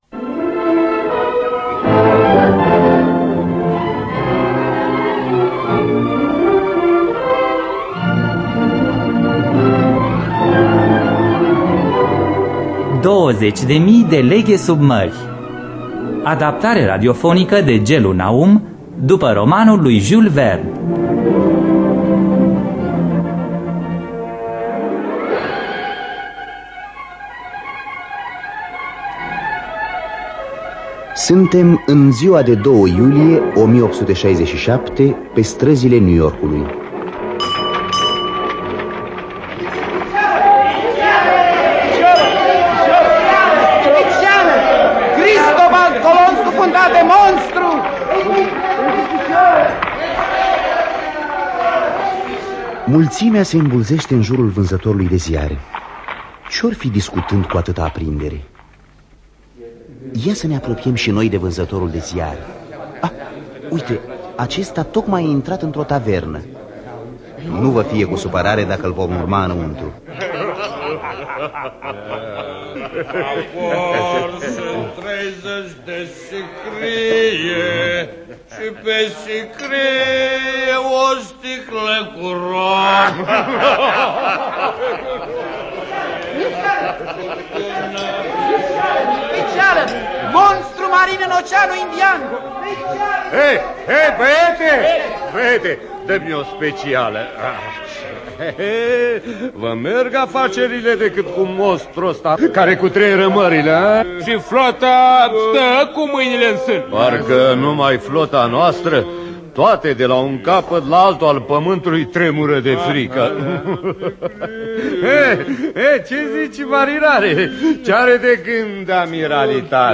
Dramatizarea radiofonică de Gellu Naum.